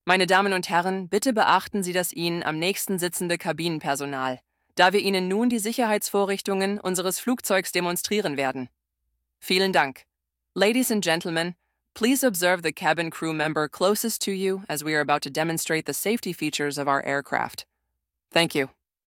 PreSafetyBriefing.ogg